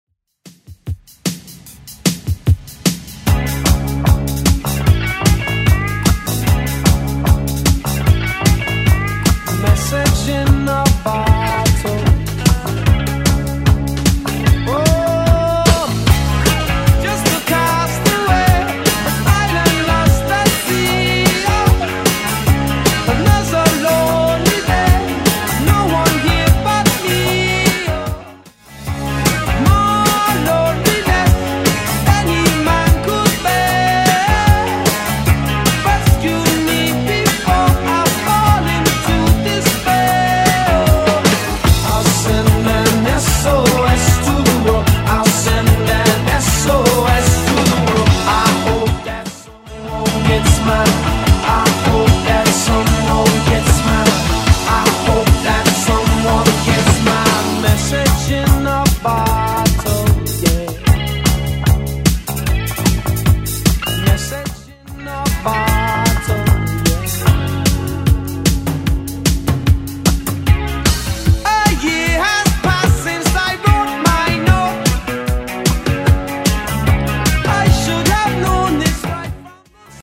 Genre: 70's
BPM: 150